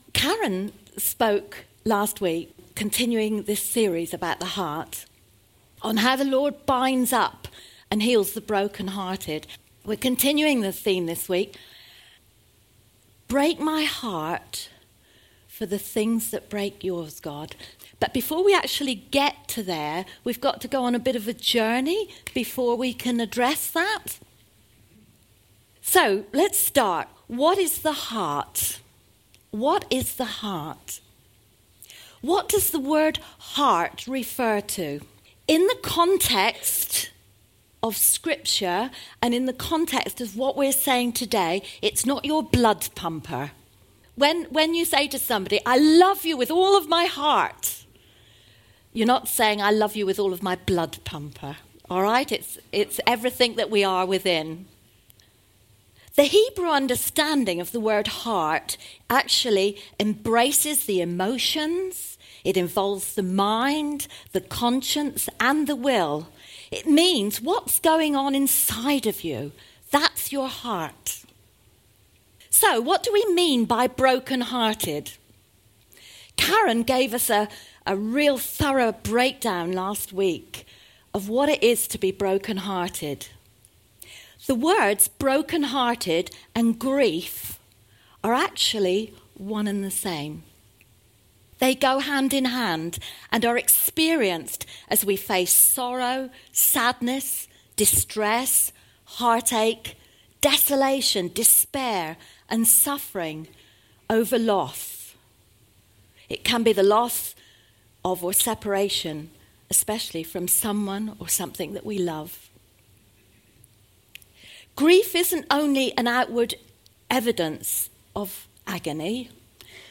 2 July 2017 sermon
2-July-2017-sermon.mp3